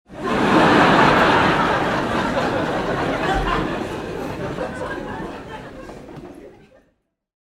Звуки закадрового смеха
Звук смеха толпы в просторном зале